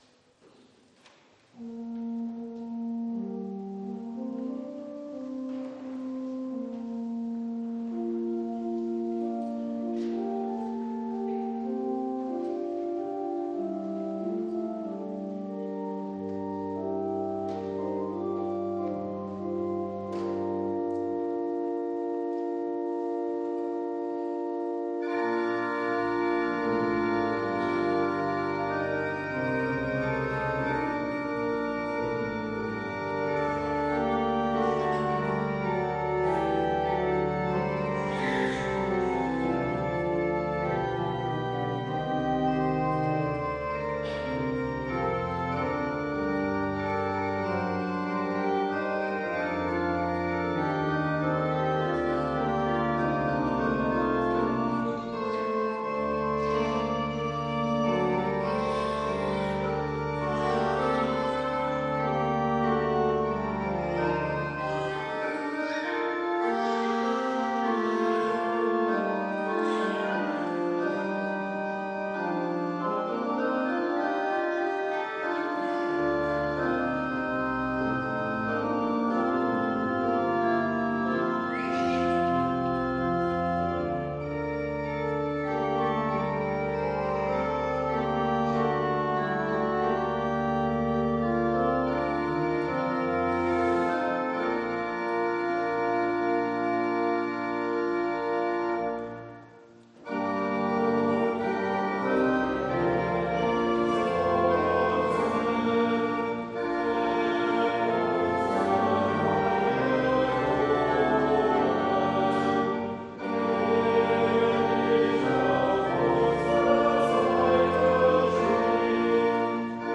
Audiomitschnitt unseres Gottesdienstes vom 17. Sonntag nach Trinitatis 2022.